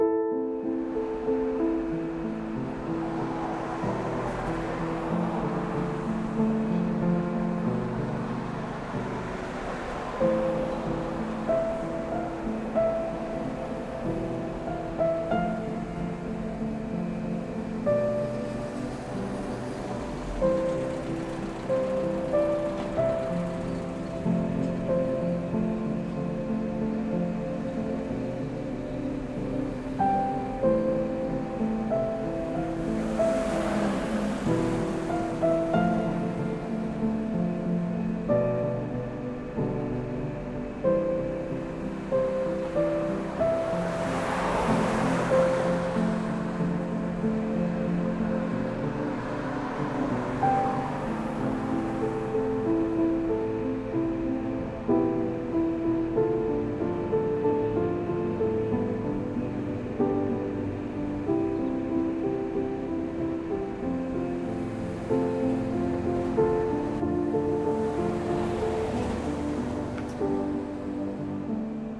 City SFX.ogg